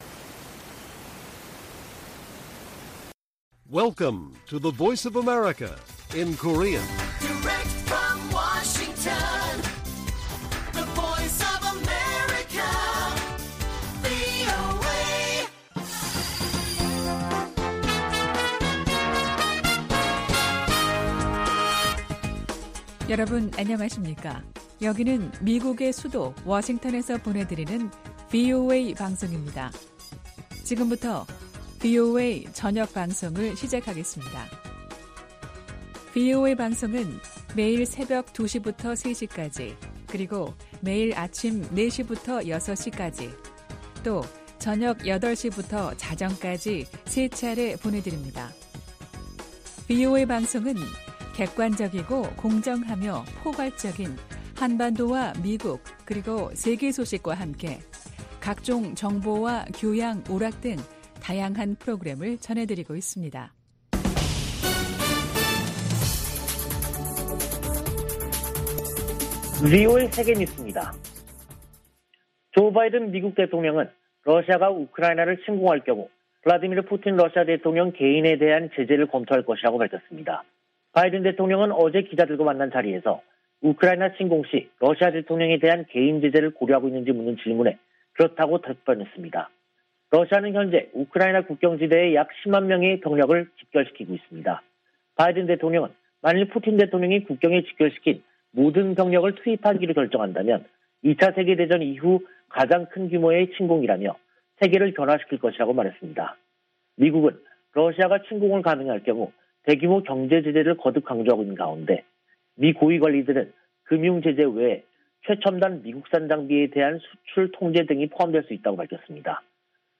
VOA 한국어 간판 뉴스 프로그램 '뉴스 투데이', 2022년 1월 26일 1부 방송입니다. 미 국무부는 북한이 순항미사일 2발을 발사한 것과 관련, 여전히 평가 중이라고 밝히고, 대북 정책 목표는 여전히 한반도의 완전한 비핵화라고 확인했습니다.